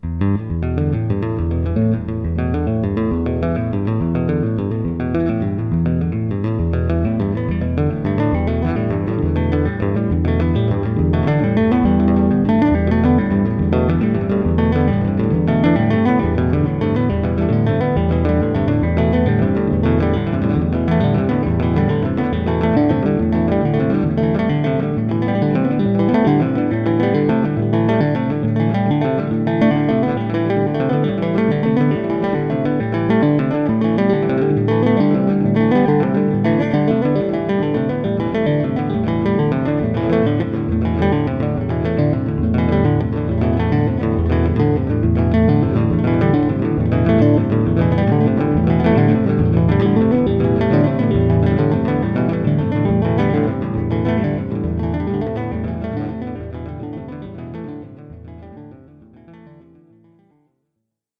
Some tonal liberties taken.